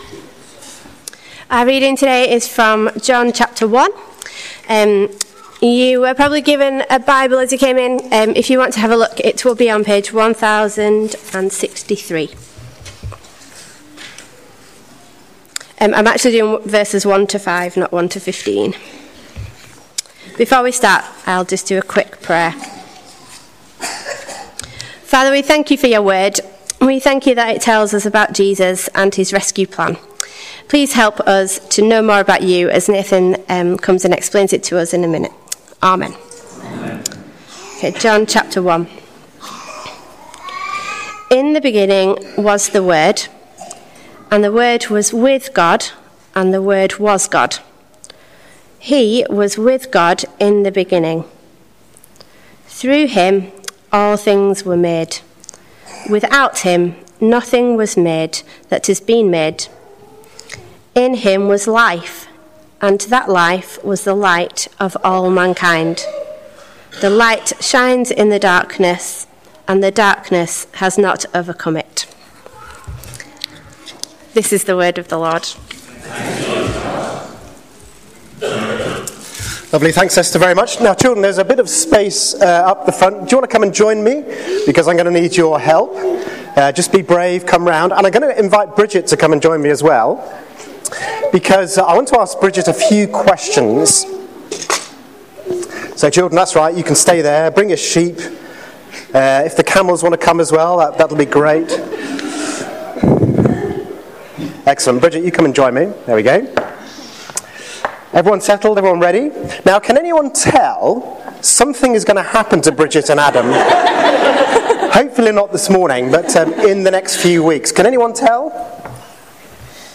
John 1:1-5 – All Age Service